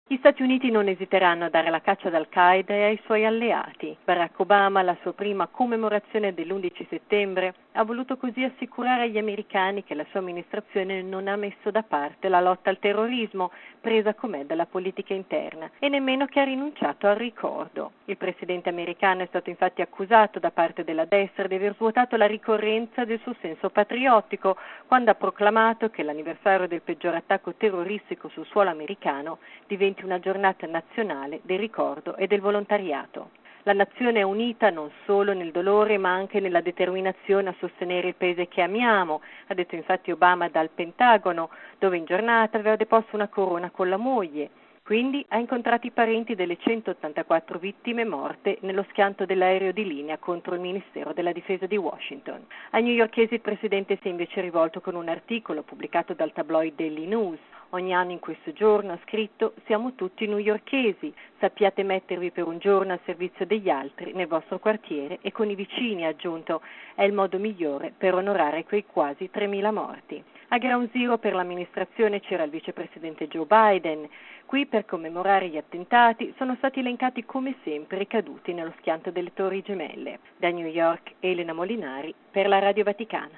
Tradizionale cerimonia anche a Ground Zero. Da New York